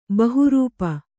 ♪ bahu rūpa